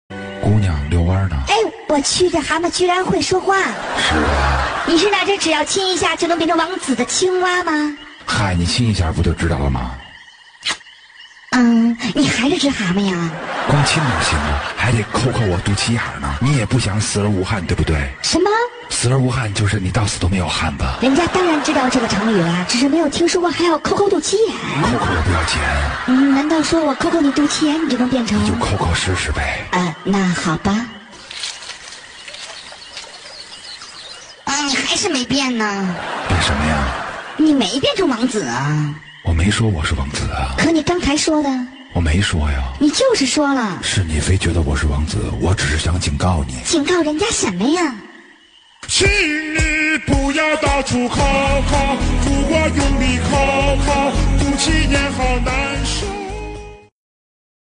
搞笑手机铃声